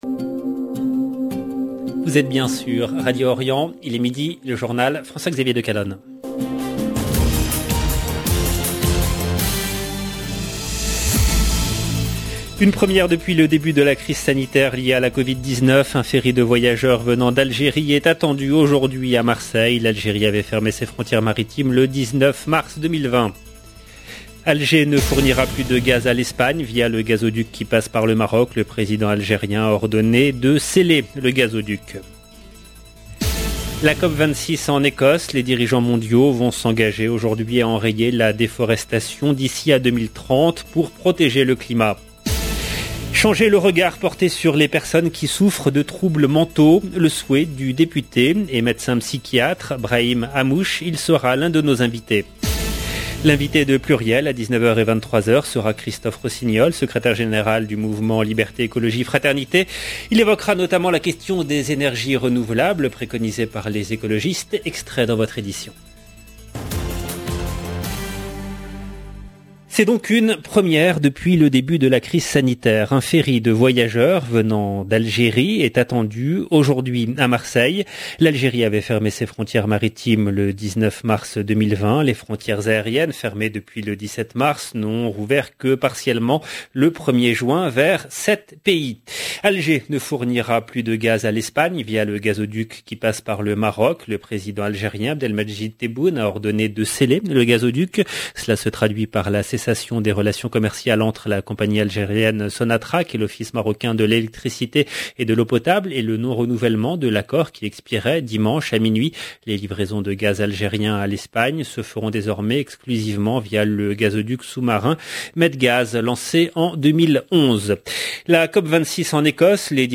EDITION DU JOURNAL EN LANGUE FRANCAISE 2/11/2021